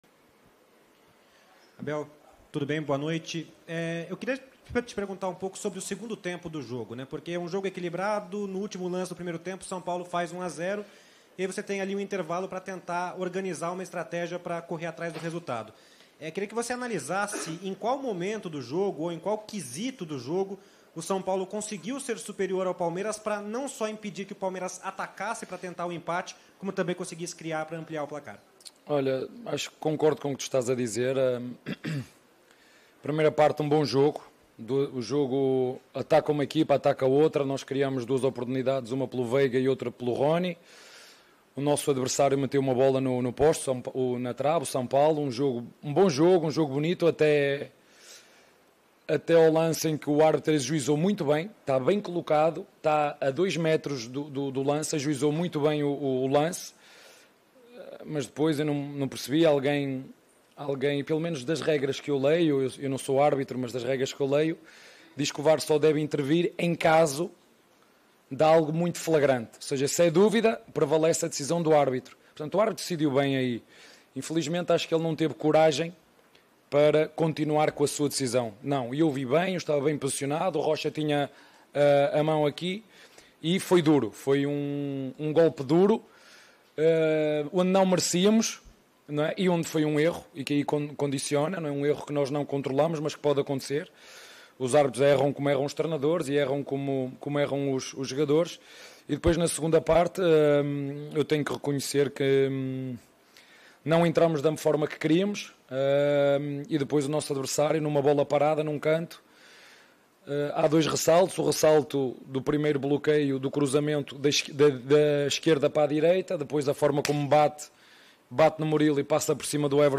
COLETIVA-ABEL-FERREIRA-_-SAO-PAULO-X-PALMEIRAS-_-PAULISTA-2022.mp3